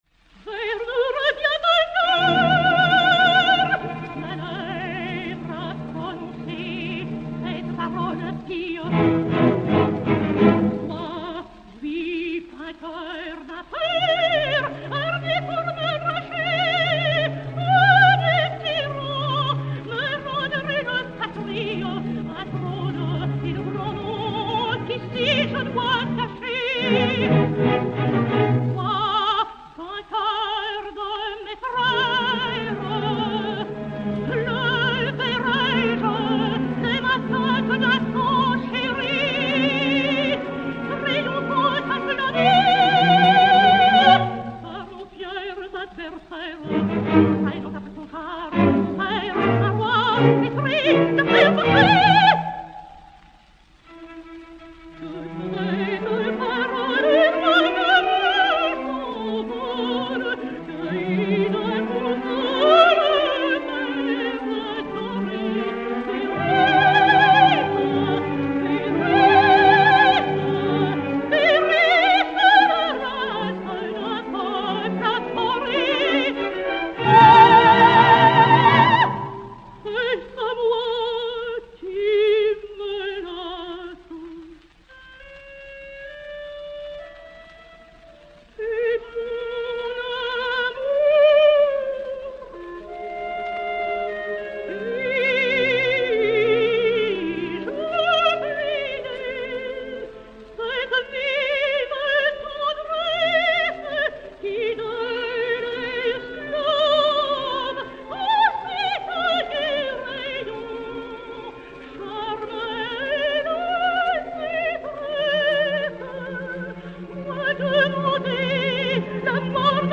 Germaine Martinelli et Orchestre dir. Albert Wolff